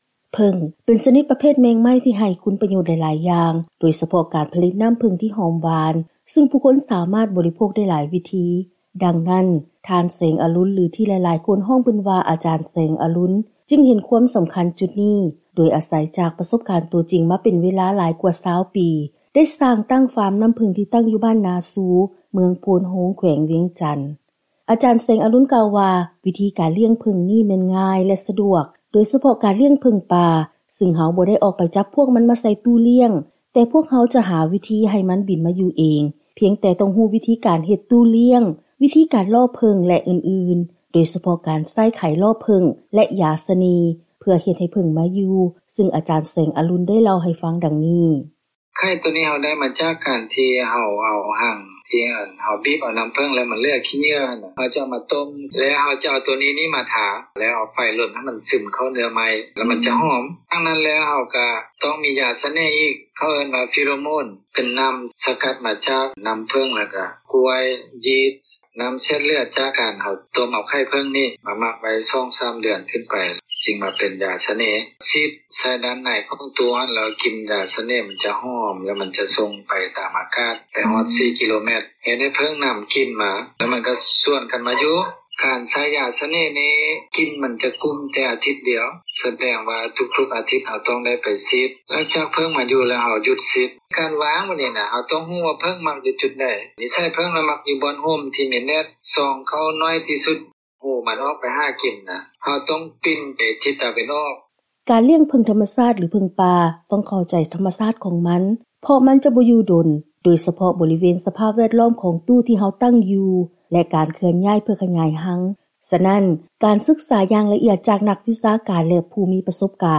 ເຊີນຟັງລາຍງານກ່ຽວກັບ ການເຮັດທຸລະກິດ ຈາກການສ້າງຟາມລ້ຽງເຜິ້ງທໍາມະຊາດ